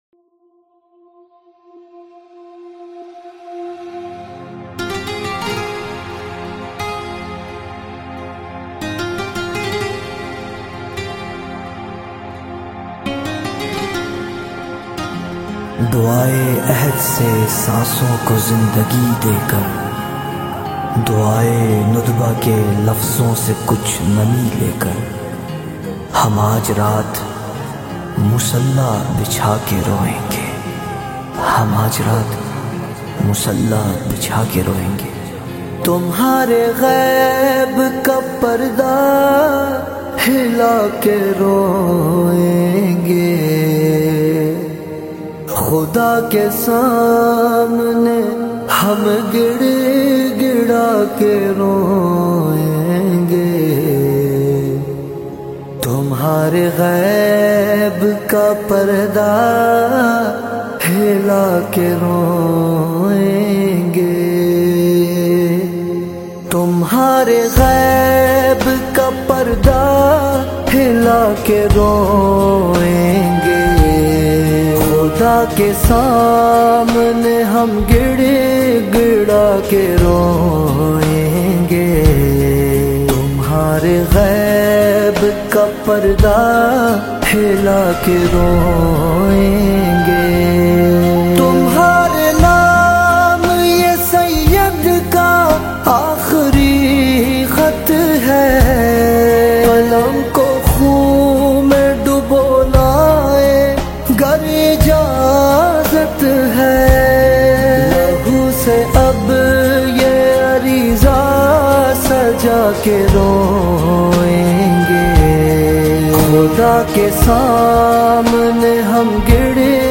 نماهنگ پاکستانی
با نوای دلنشین